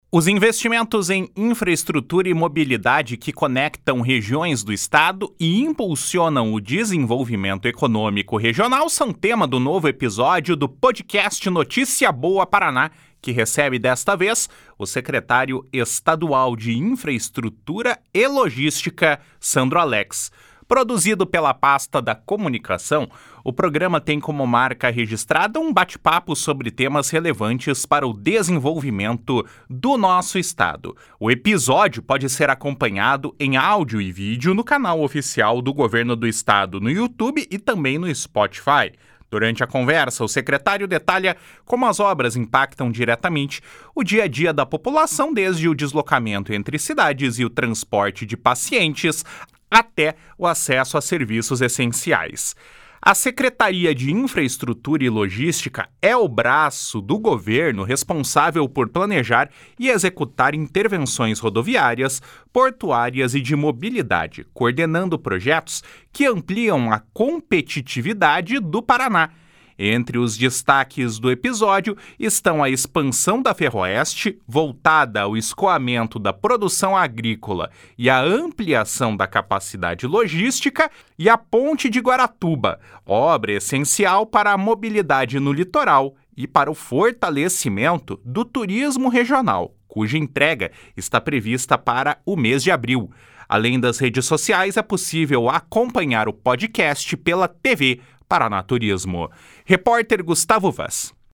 Os investimentos em infraestrutura e mobilidade que conectam regiões do Estado e impulsionam o desenvolvimento econômico regional são tema do novo episódio do podcast Notícia Boa Paraná, que recebe o secretário estadual de Infraestrutura e Logística, Sandro Alex. Produzido pela Secretaria de Estado da Comunicação, o programa tem como marca registrada um bate-papo sobre temas relevantes para o desenvolvimento do Estado.